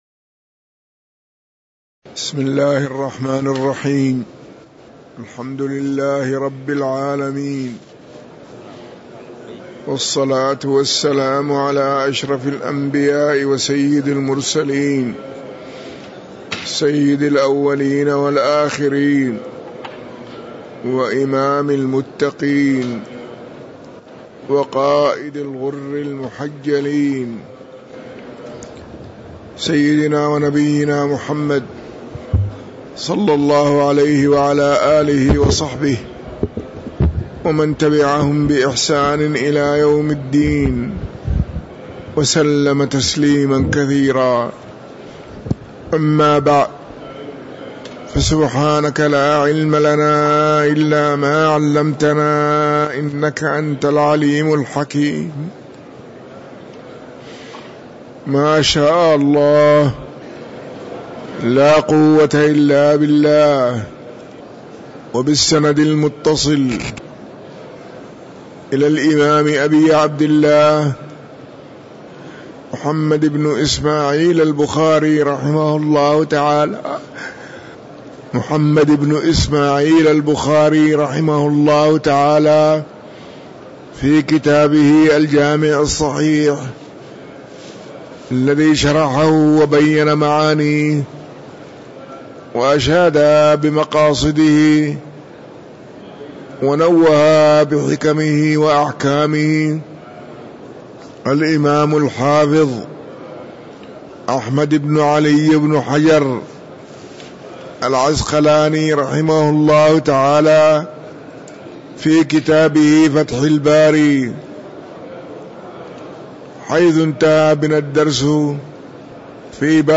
تاريخ النشر ١٠ شوال ١٤٤٣ هـ المكان: المسجد النبوي الشيخ